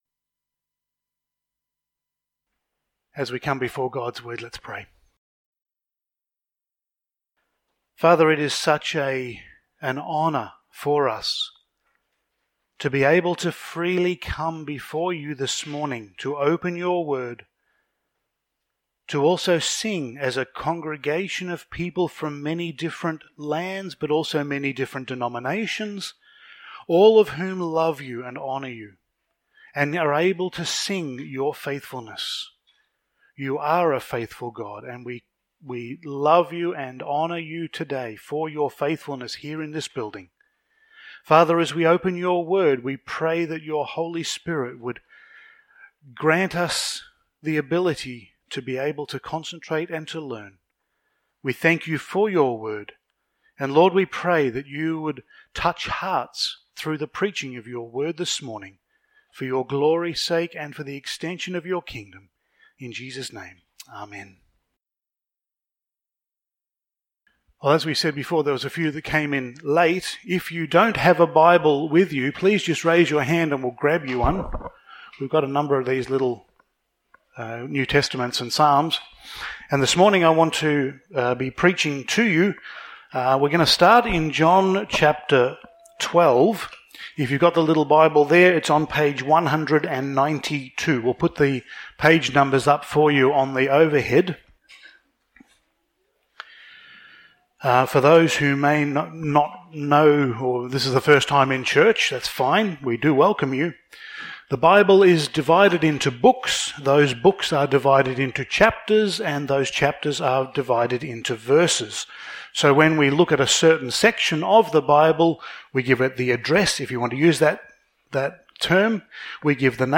Topical Sermon Passage: Psalm 31:5